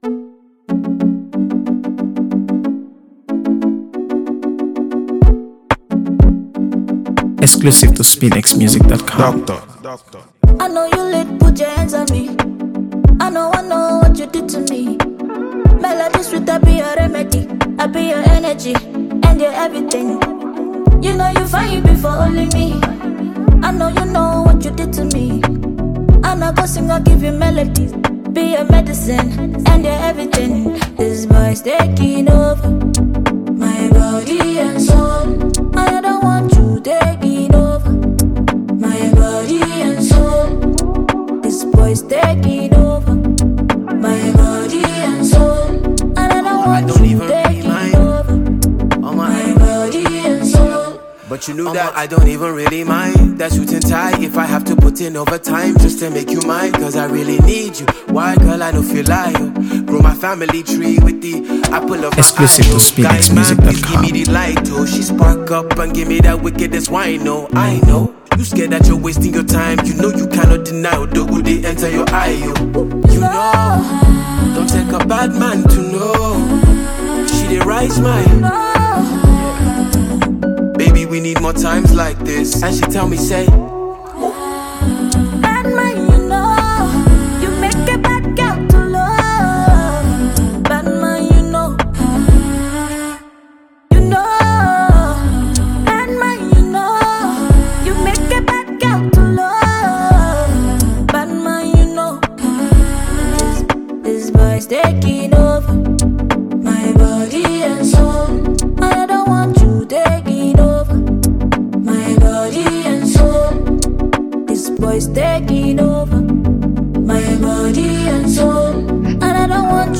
AfroBeats | AfroBeats songs
Nigerian rapper
melodic charm